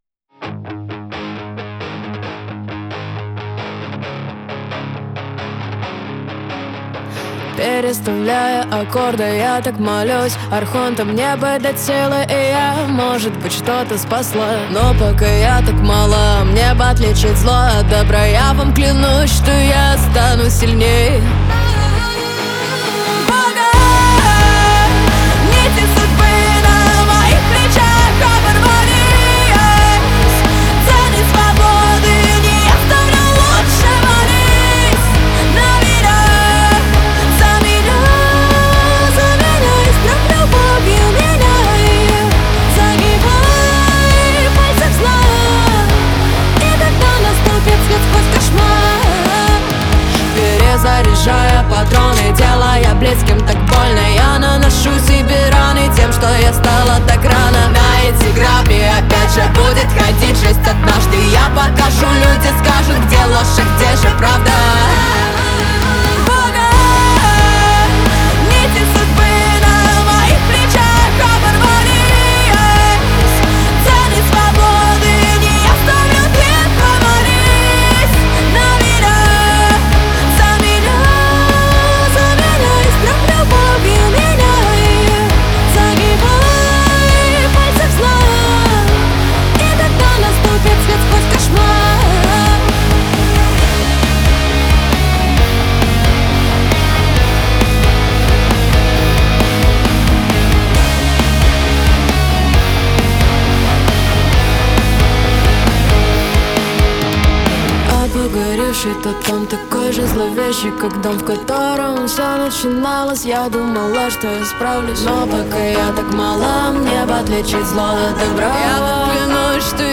Дип хаус